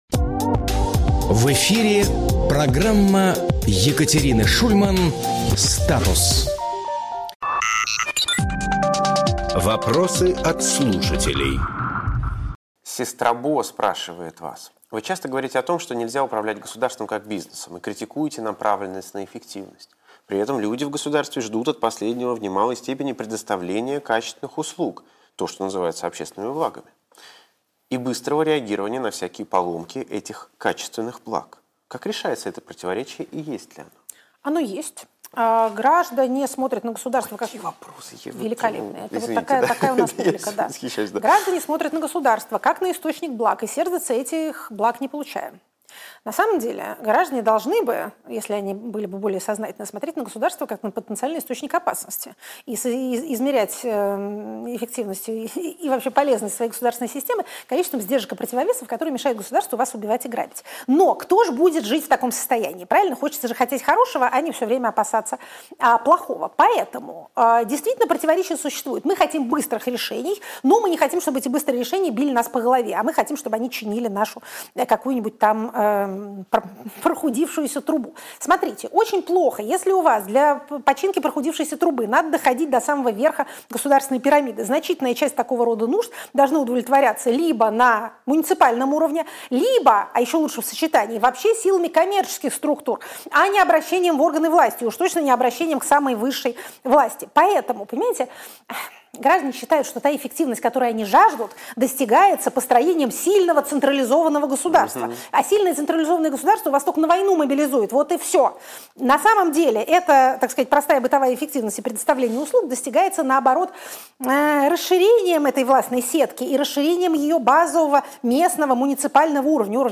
Екатерина Шульманполитолог
Фрагмент эфира от 27.01.2026